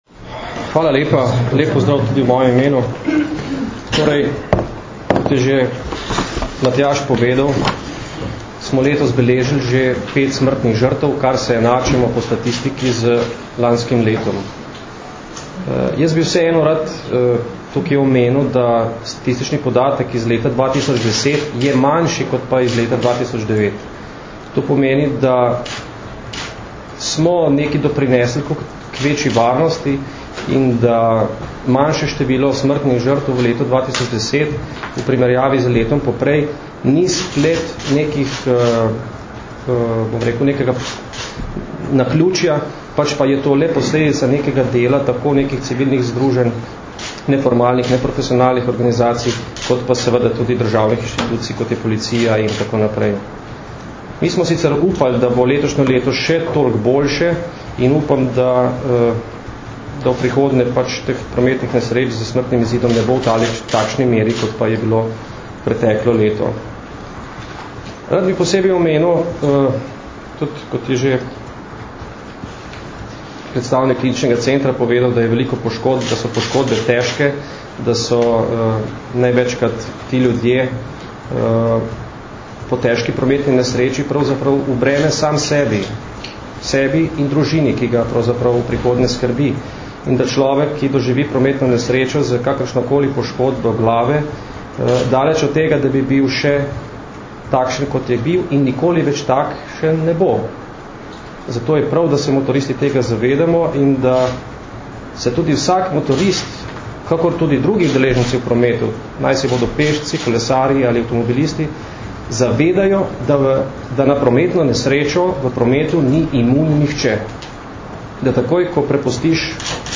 Zdravniki, policisti in motoristi skupaj za varno motoristično sezono - informacija z novinarske konference